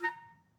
Clarinet
DCClar_stac_A#4_v1_rr1_sum.wav